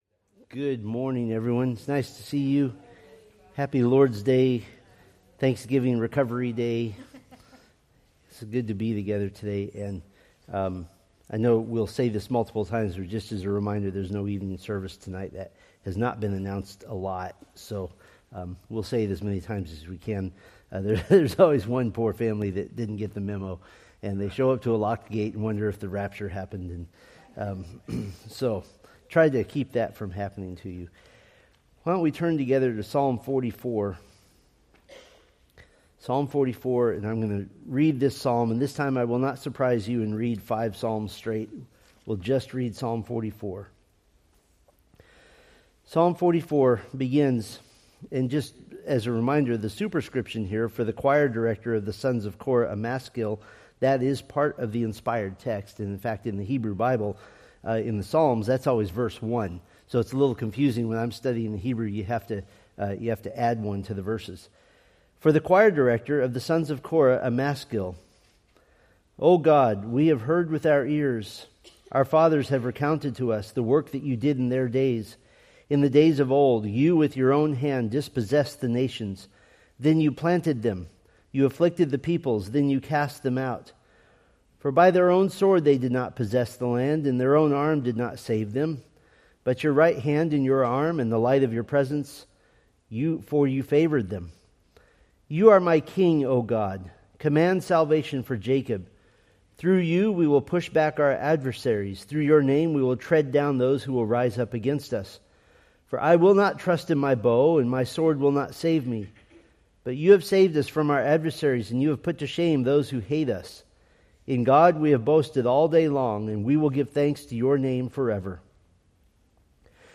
Date: Nov 30, 2025 Series: Psalms Grouping: Sunday School (Adult) More: Download MP3